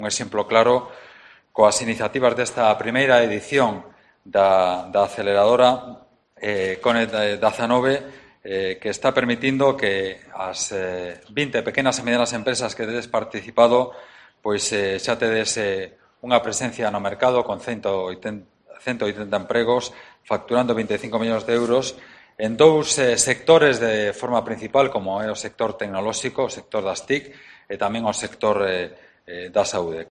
Francisco Conde, en su intervención en la Tecnópole